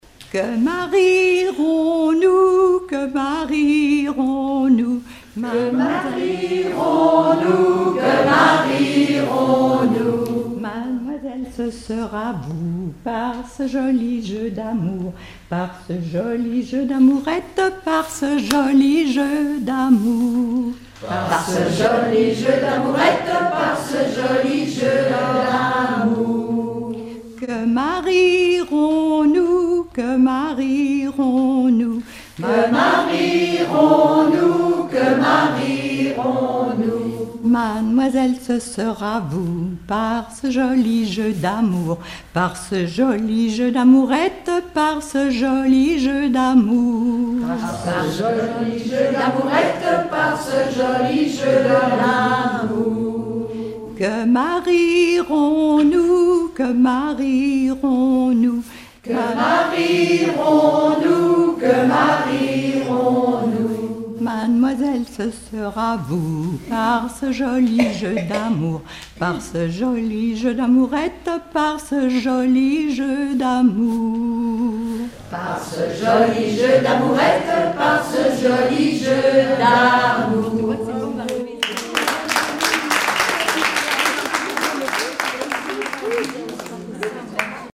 Rondes enfantines à baisers ou mariages
Regroupement de chanteurs du canton
Pièce musicale inédite